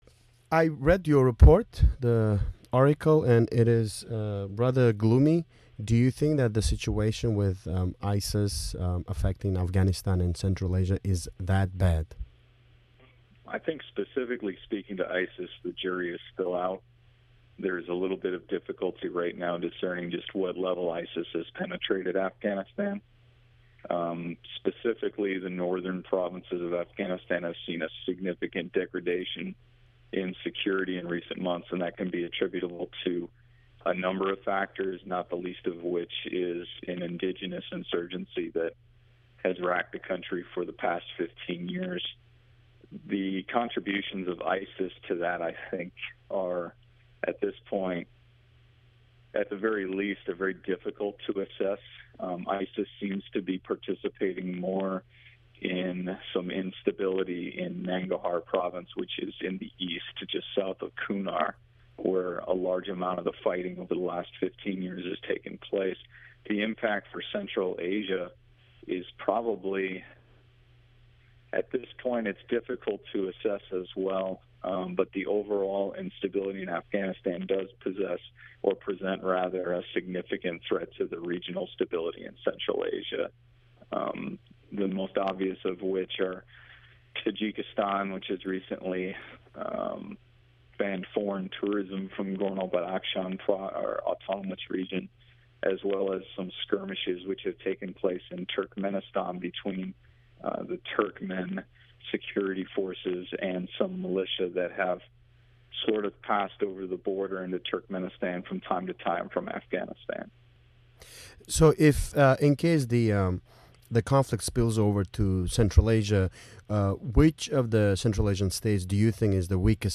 Amerikalik tahlilchi bilan "Islomiy davlat"ning Markaziy Osiyoga tahdidi haqida intervyu (o'zbeckha)